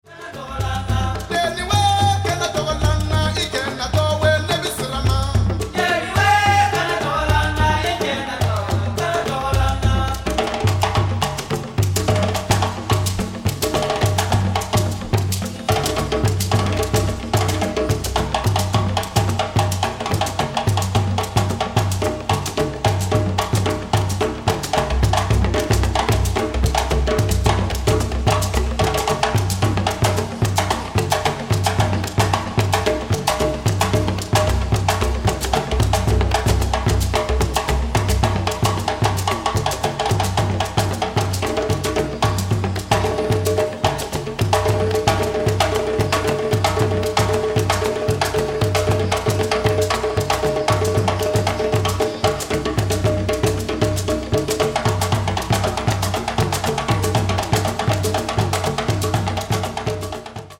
balafon player